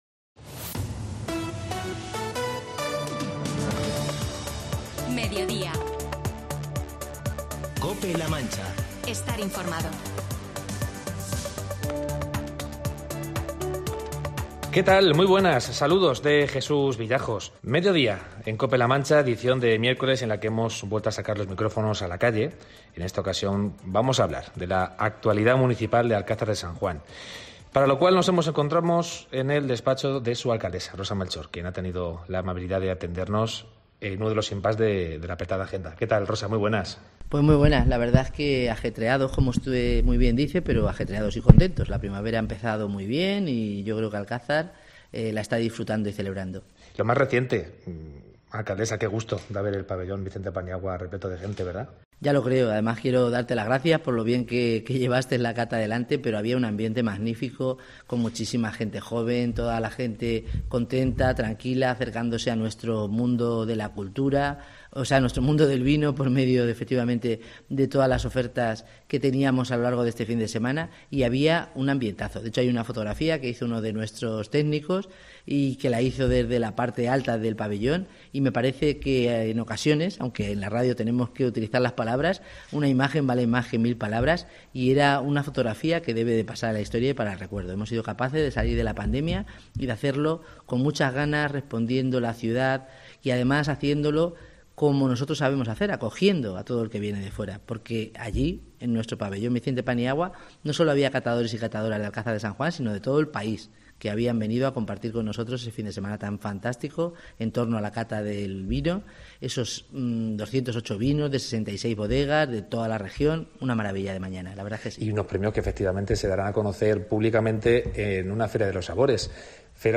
Entrevista a la alcaldesa de Alcázar de San Juan, Rosa Melchor
AUDIO: La alcaldesa de Alcázar de San Juan, Rosa Melchor, se ha pasado por los micrófonos de Mediodía Cope La Mancha para abordar...